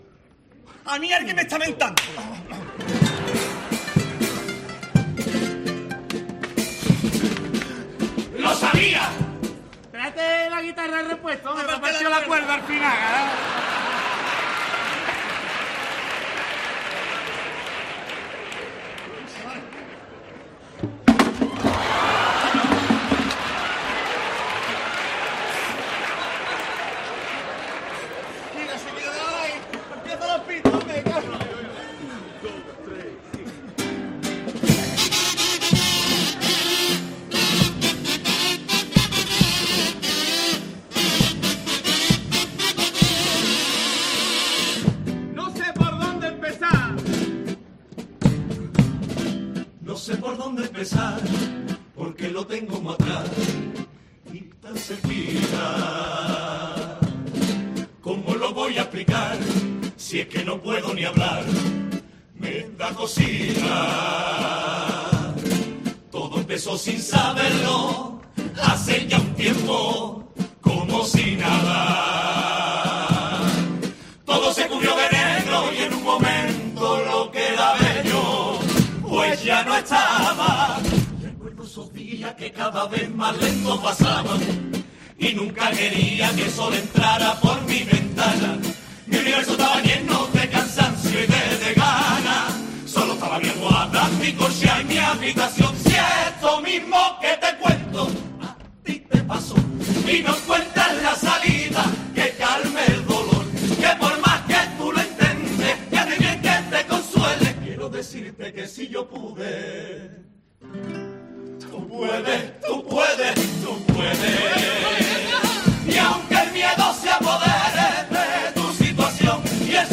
Pasodoble a la salud mental de la chirigota de Puerto Real
Un tema que ya ha salido en el escenario del Gran Teatro Falla gracias a la chirigota 'To me pasa a mi, los desgraciaitos'.